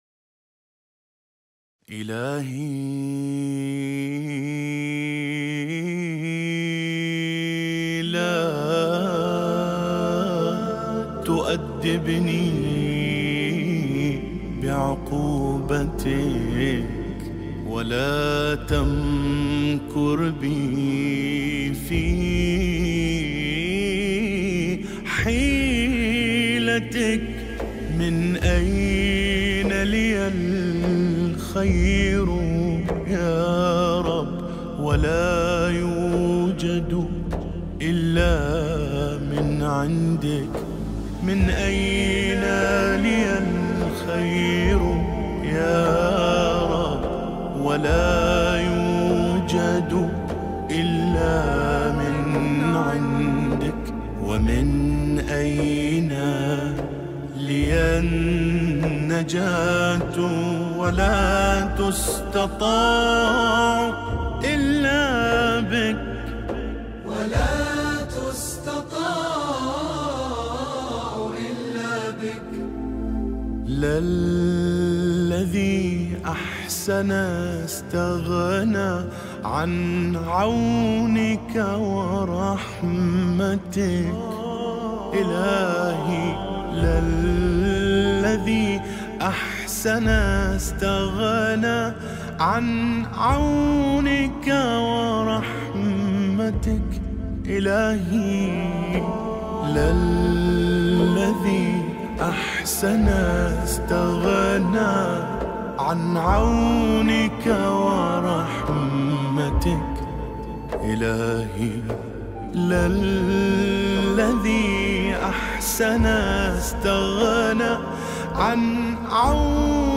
دعاء للإمام زین العابدین(ع)